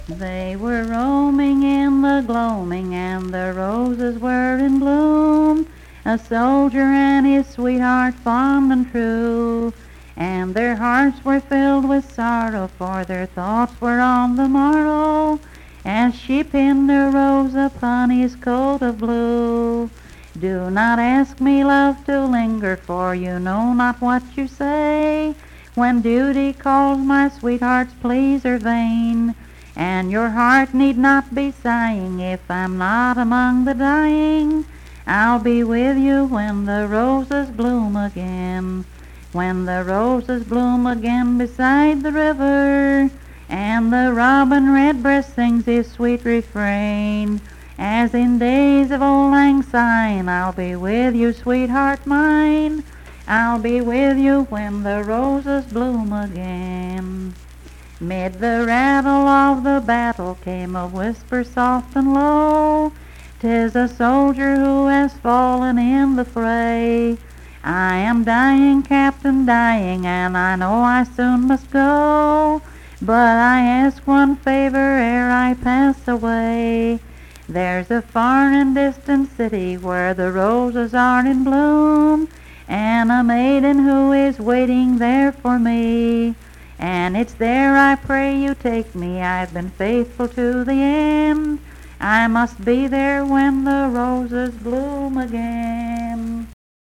Unaccompanied vocal music performance
Verse-refrain 5d(4).
Voice (sung)